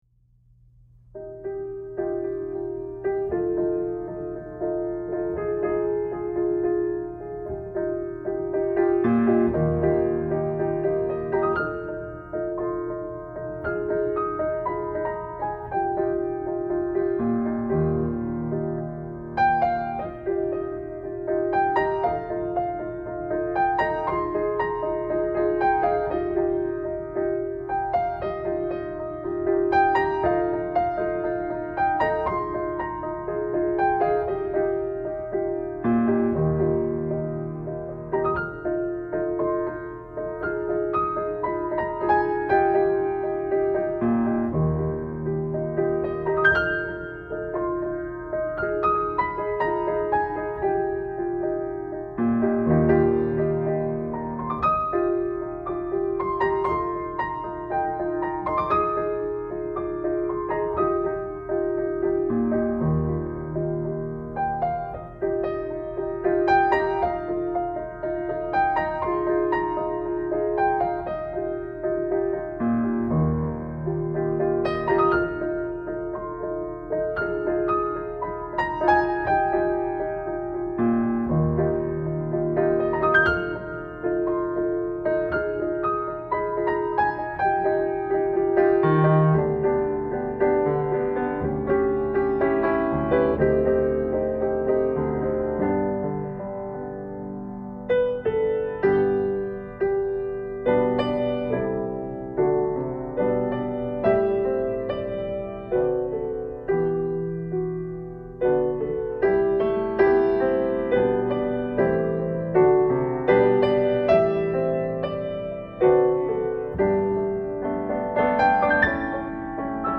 June-14-Sanctuary-audio-1.mp3